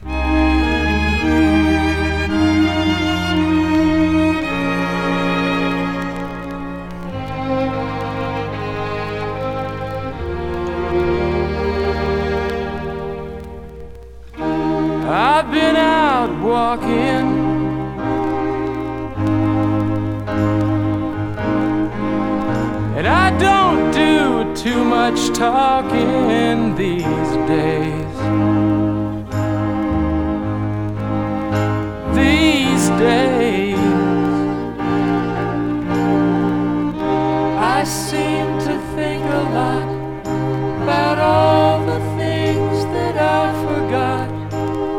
Rock, Pop, Country Rock　USA　12inchレコード　33rpm　Stereo